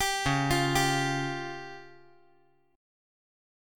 DbMb5 Chord
Listen to DbMb5 strummed